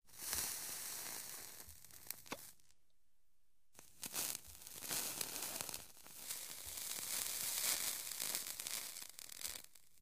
Kurenie 2 sec.ogg